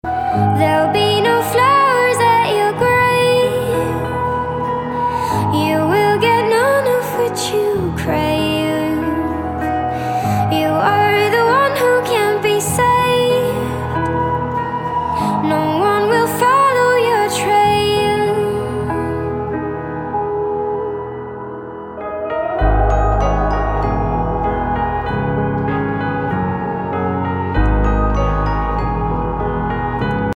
• Качество: 256, Stereo
поп
женский вокал
спокойные
красивый женский голос